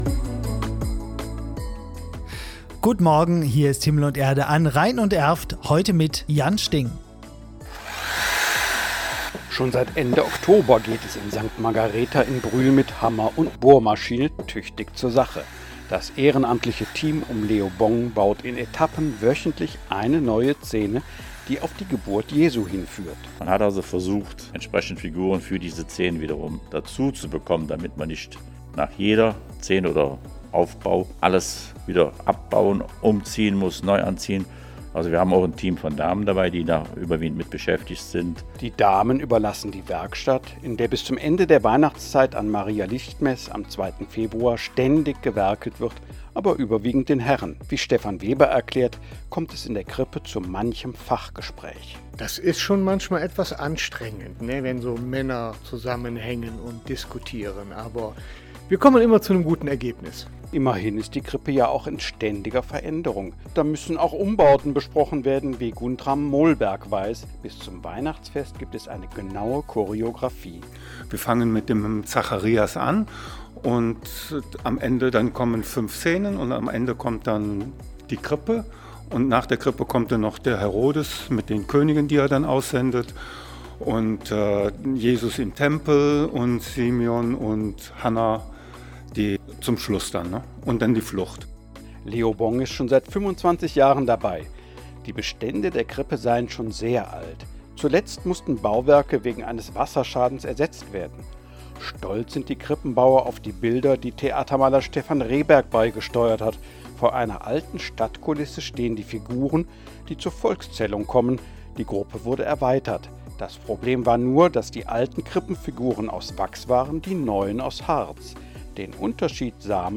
Interview_Krippenbauern.mp3